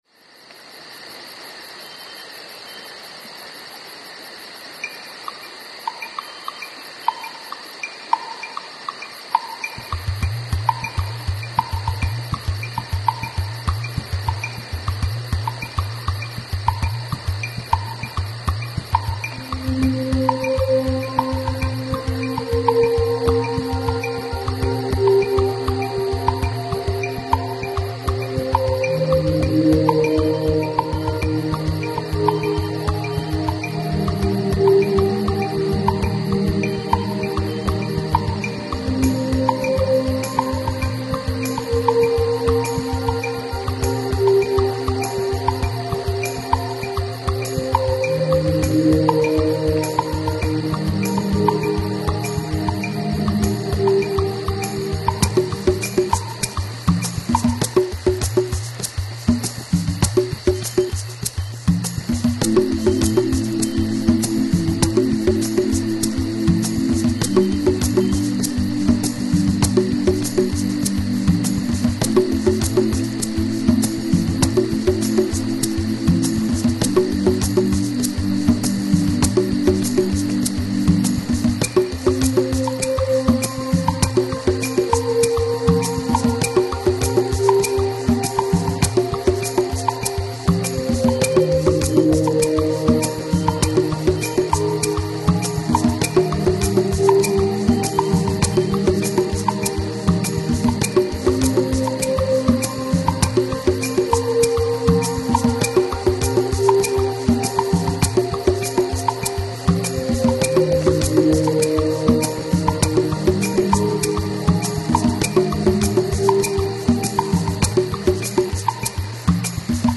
I sometimes write techno songs under the band name "The Pure Virtual Method".
Comes The Rain The most mellow of the songs, this is like ambient or down-tempo.